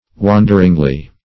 wanderingly - definition of wanderingly - synonyms, pronunciation, spelling from Free Dictionary Search Result for " wanderingly" : The Collaborative International Dictionary of English v.0.48: Wanderingly \Wan"der*ing*ly\, adv.